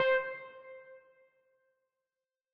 Pluck - Dot.wav